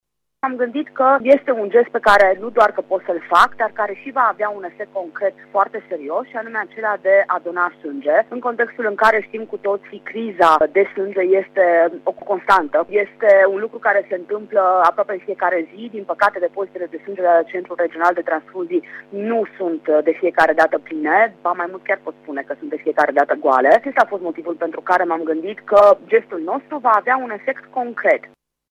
Ioana Roman a spus, pentru RTM , că a dorit ca de ziua ei să facă un gest pentru alţii: